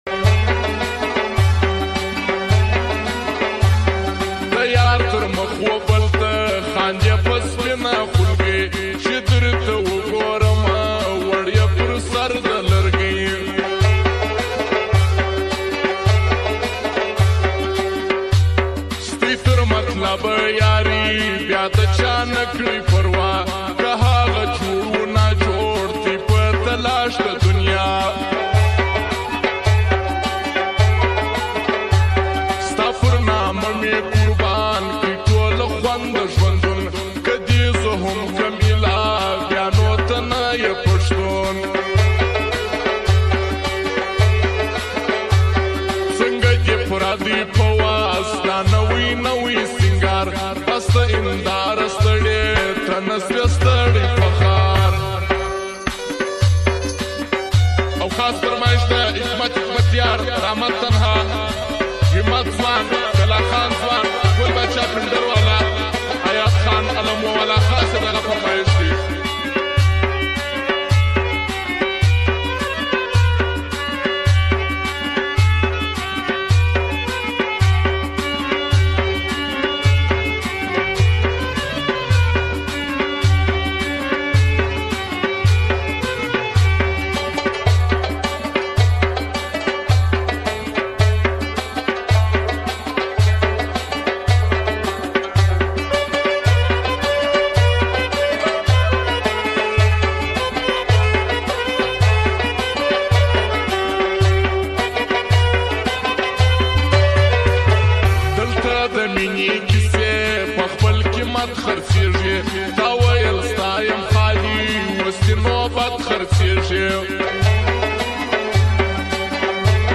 پشتو سونگ